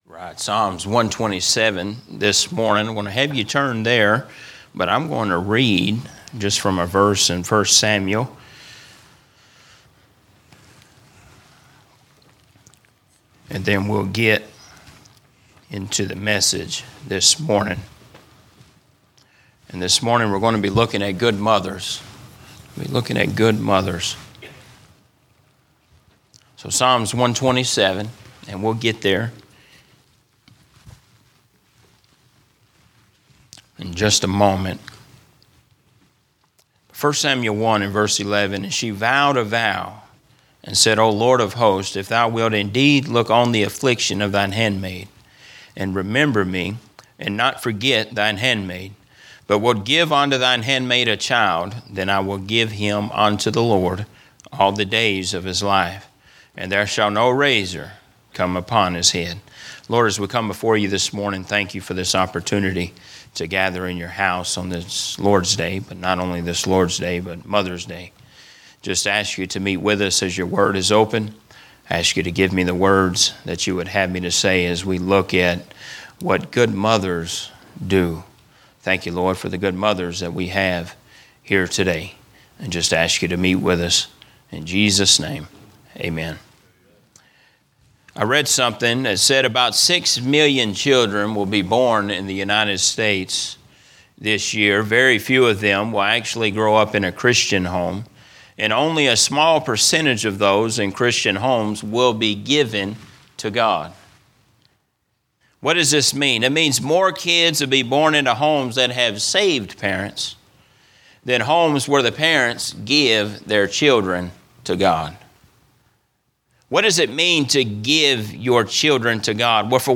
Sermons - Emmanuel Baptist Church
From Series: "General Preaching"